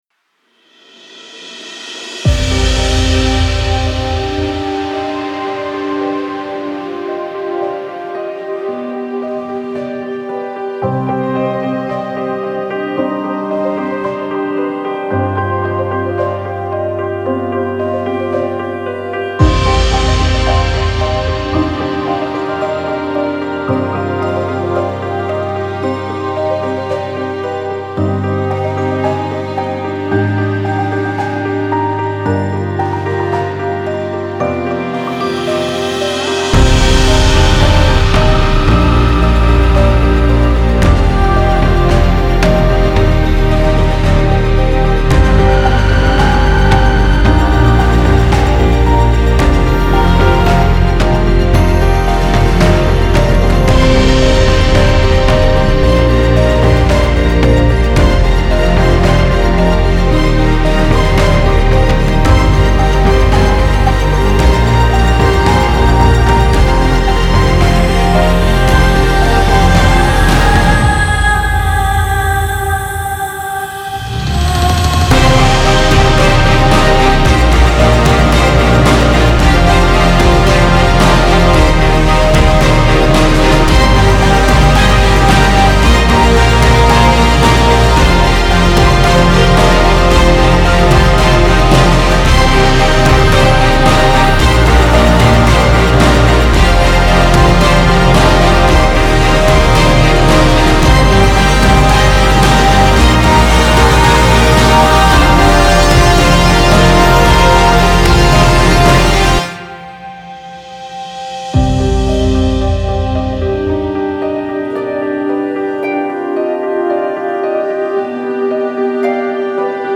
موسیقی بی کلام حماسی
Trailer Music
موسیقی بی کلام تریلر